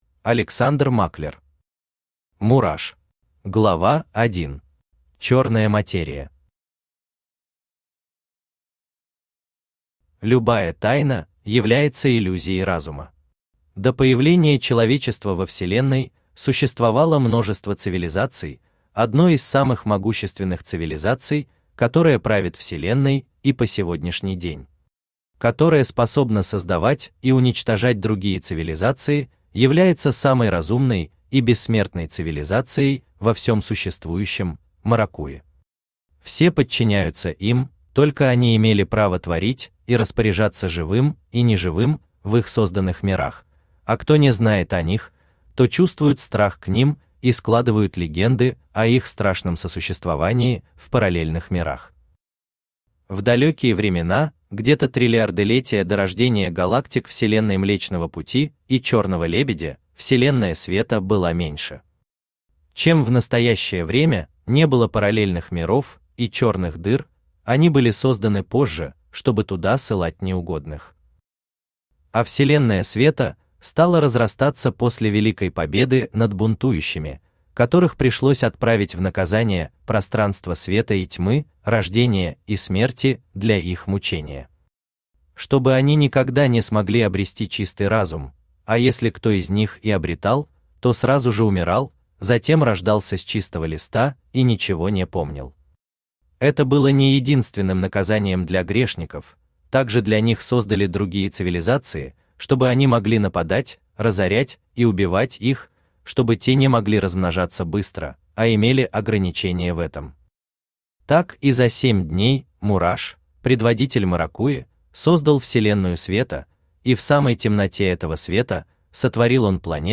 Аудиокнига Мураш | Библиотека аудиокниг
Aудиокнига Мураш Автор Александр Германович Маклер Читает аудиокнигу Авточтец ЛитРес.